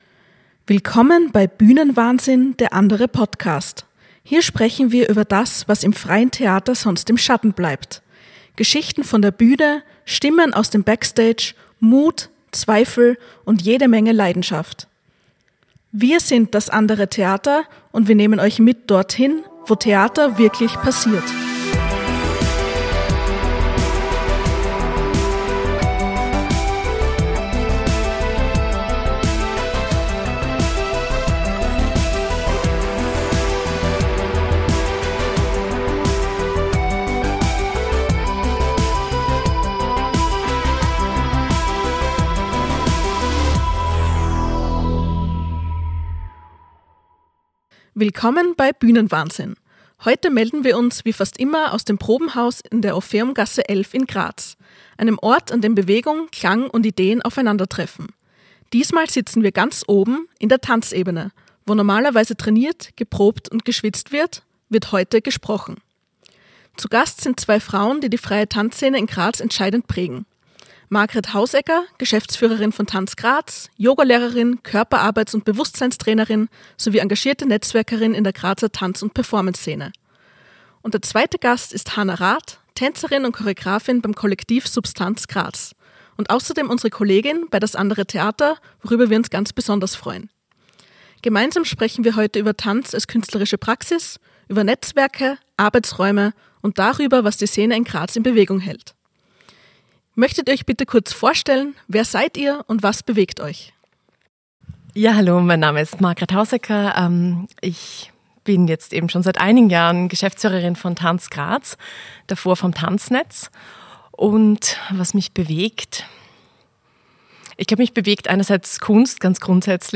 Gemeinsam sprechen wir über Bewegung als Sprache, über Räume für künstlerische Praxis, über Netzwerke, Sichtbarkeit und die Zukunft der Tanzszene in Graz. Ein Gespräch über Leidenschaft, Struktur – und darüber, was Tanz eigentlich alles bewegen kann. Jetzt reinhören – direkt aus der Tanzebene im Probenhaus, über den Dächern von Graz.